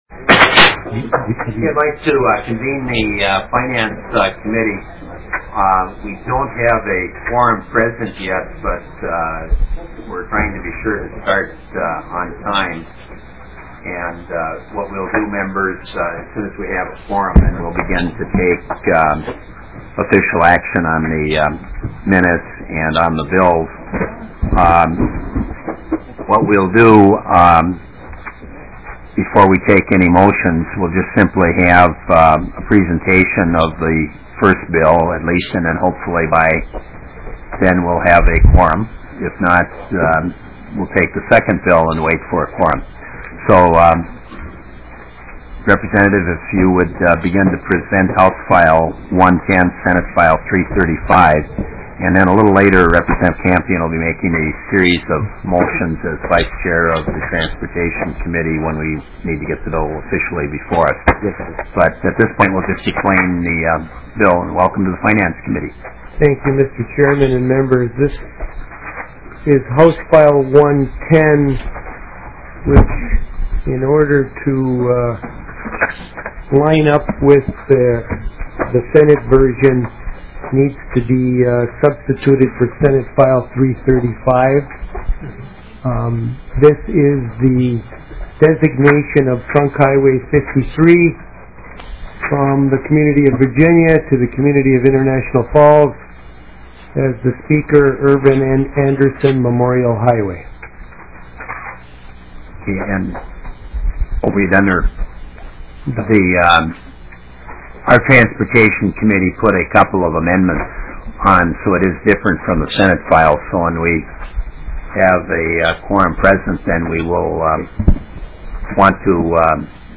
00:28 - Gavel. 01:10 - SF335 (Anzelc) Irv Anderson Memorial Highway designated. 09:50 - HF121 (Sailer) Clearwater County Veterans Memorial Highway designated. 13:20 - HF1040 (Shimanski) McLeod West; ISD 2887; reorganization operating debt bonds issued.